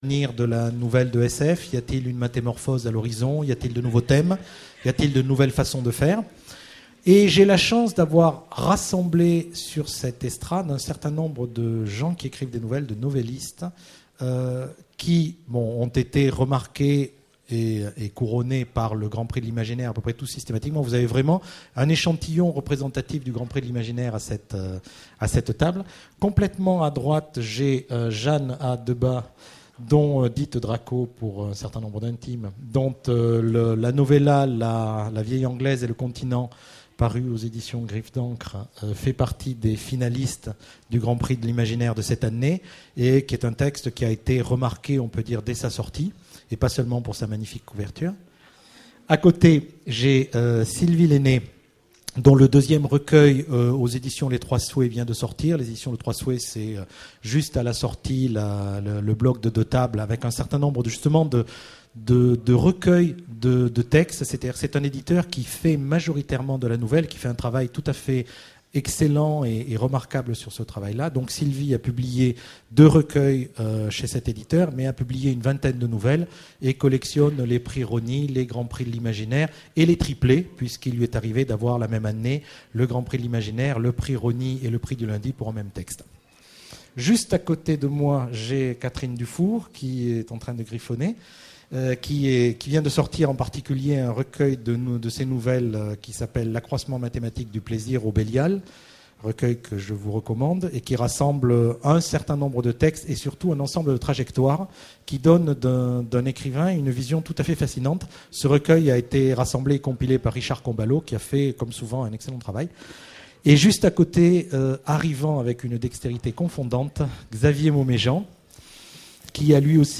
Voici l'enregistrement de la conférence sur les nouvelles aux Utopiales 2008.